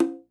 Conga2Hi.wav